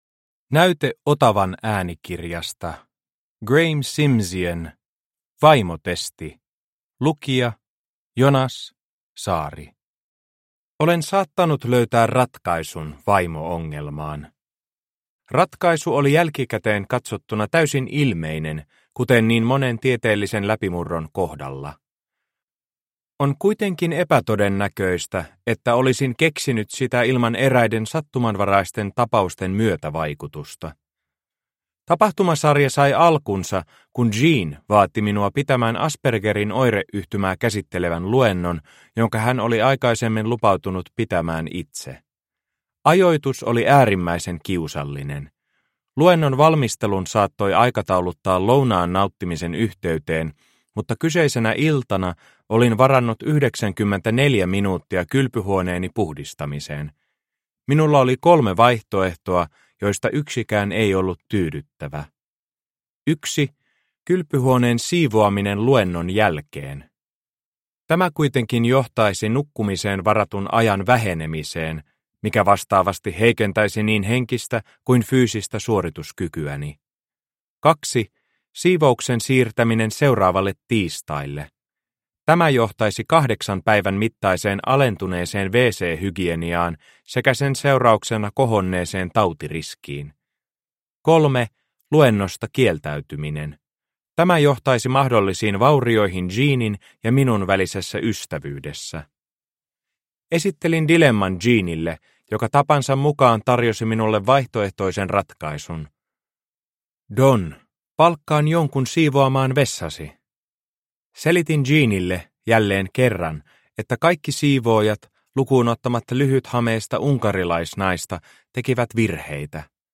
Vaimotesti – Ljudbok – Laddas ner